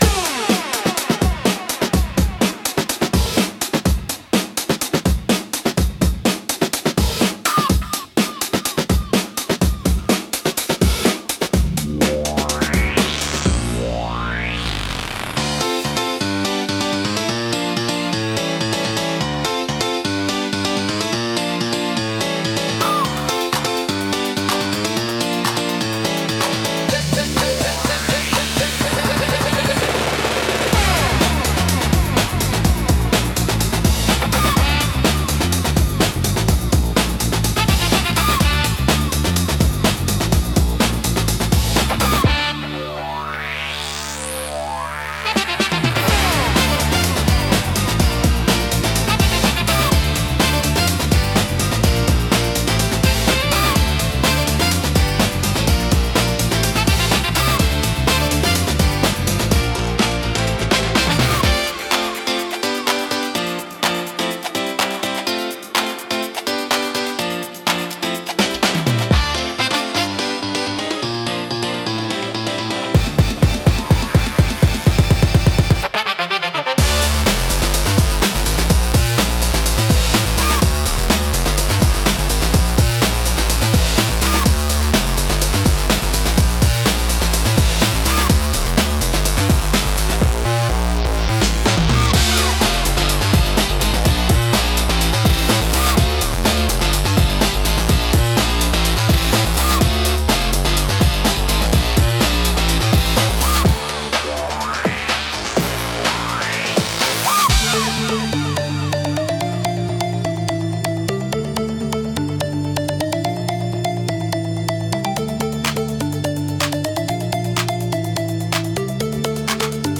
躍動感を生み出し、観客やプレイヤーのテンションを上げる効果が高く、飽きさせないペースで強いインパクトを与えます。